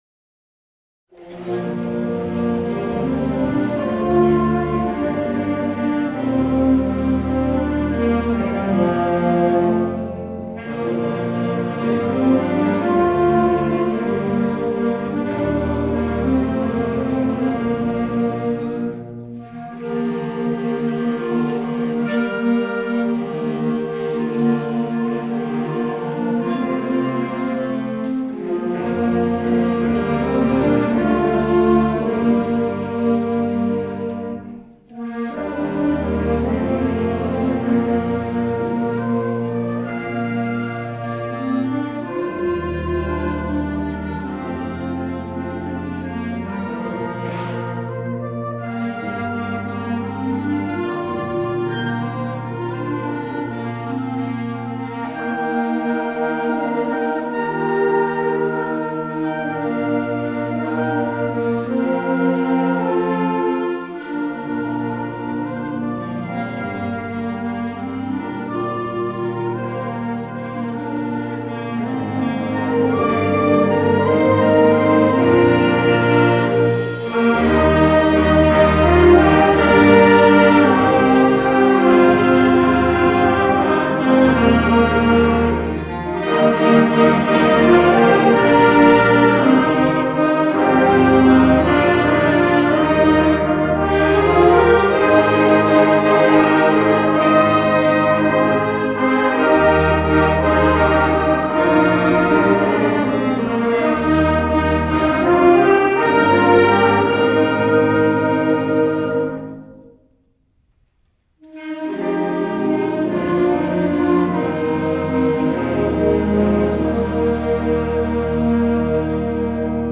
MIOSM Band Concert 6:30